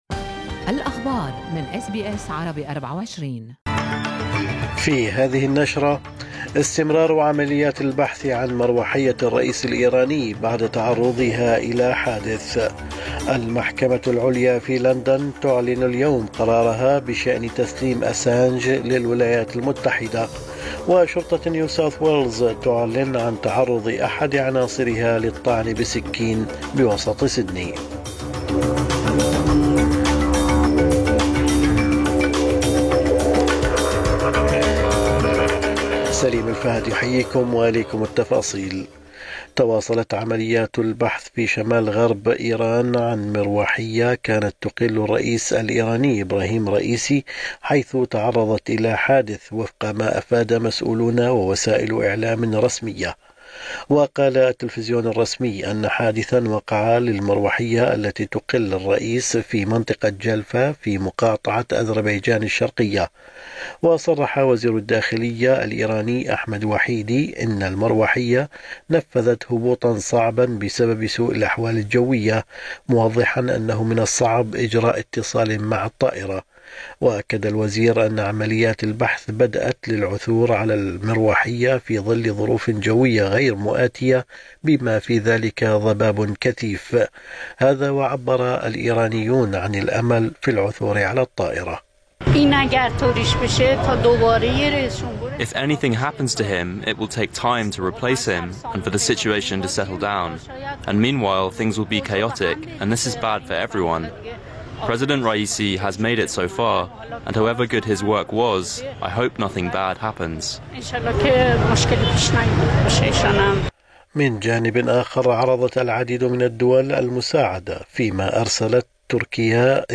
نشرة اخبار الصباح 20/5/2024